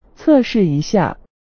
文字转语音接口-APi文档